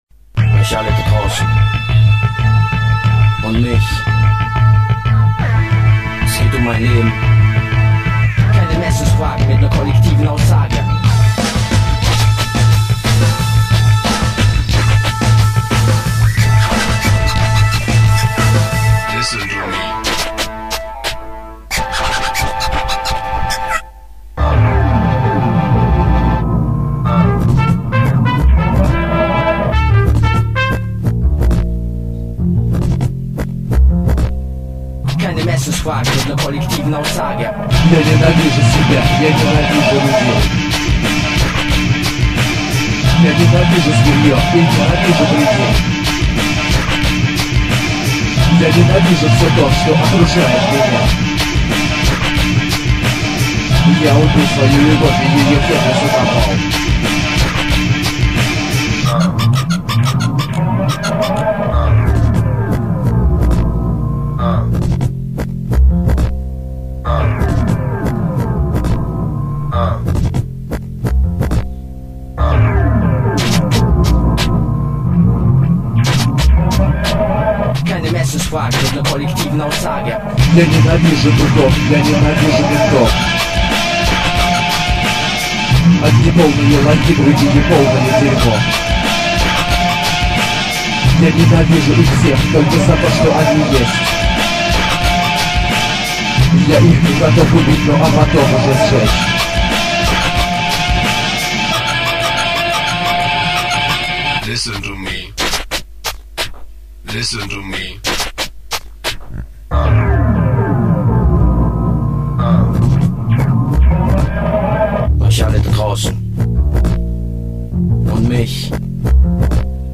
hardcore LIFE version